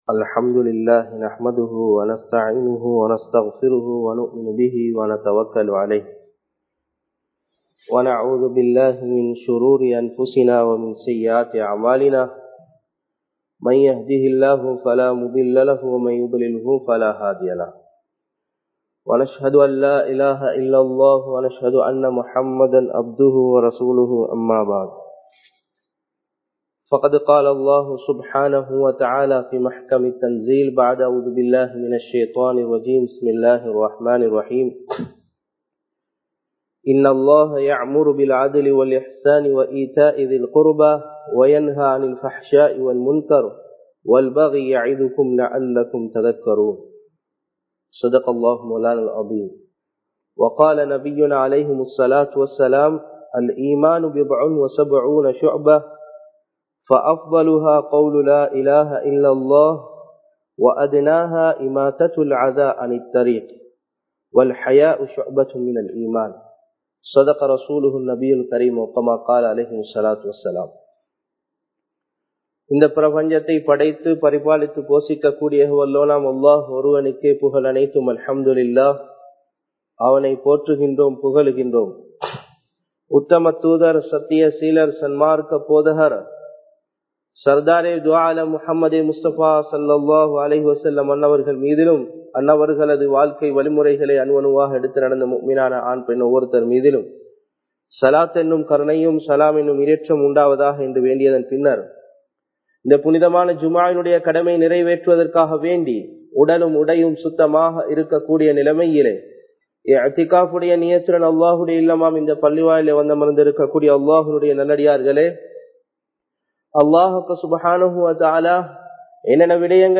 எம் நாட்டை நேசியுங்கள் | Audio Bayans | All Ceylon Muslim Youth Community | Addalaichenai
Sabeelur Rashad Jumua Masjidh